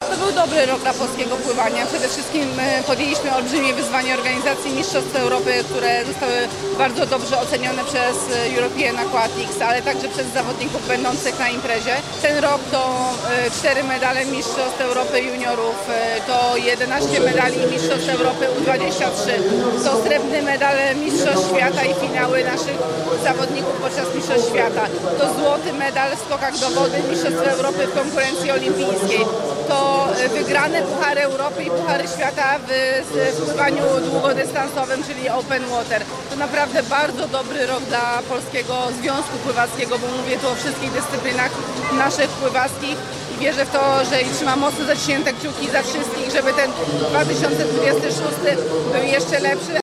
Mistrzostwa Polski w pływaniu, które w ten weekend odbyły się w Szczecinie, były ostatnią pływacką imprezą w naszym kraju w 2025 roku. Ten rok stanął jednak pod znakiem mistrzostw Europy, które na początku grudnia odbyły się w Lublinie. Mówi prezes polskiego związku pływackiego Otylia Jędrzejczak.